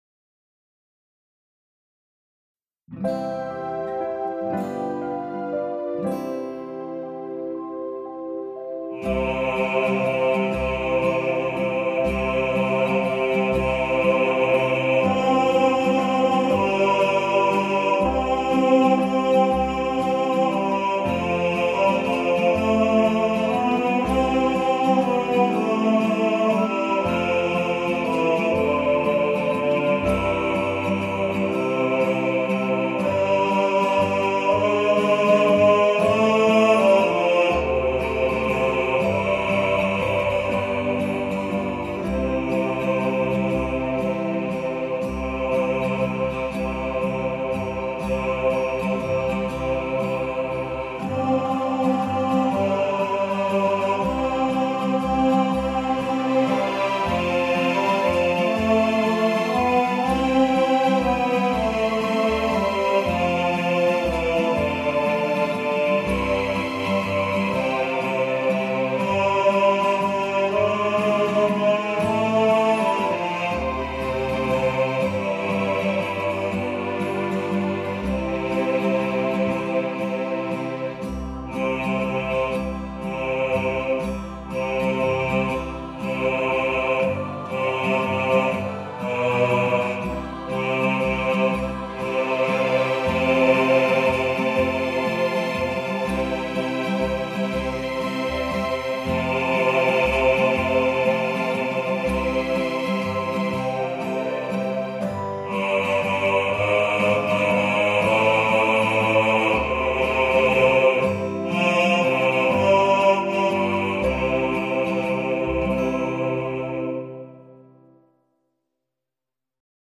Silent-Night-Bass.mp3